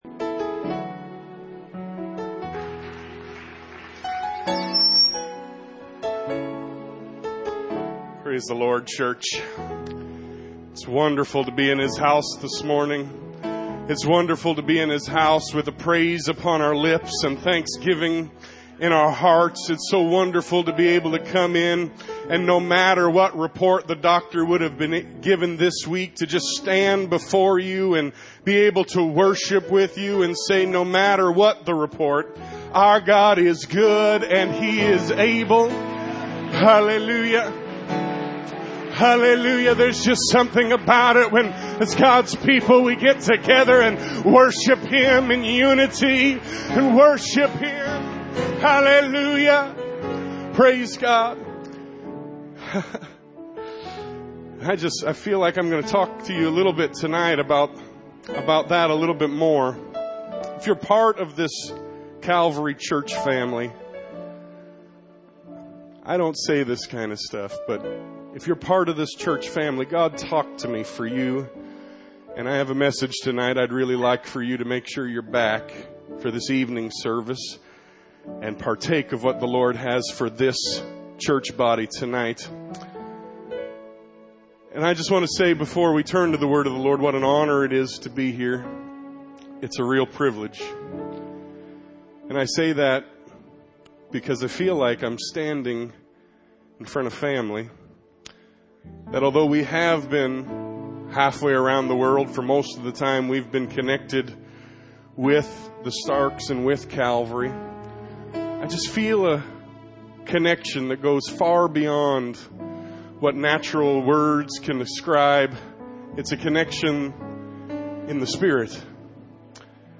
The Calvary Apostolic Church Sermon Archive
Missions Conference | Sunday Morning